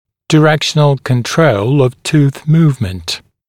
[dɪ’rekʃənl kən’trəul əv tuːθ ‘muːvmənt] [daɪ-] [ди’рэкшэнл кэн’троул ов ту:с ‘му:вмэнт] [дай-] контроль направления движения зуба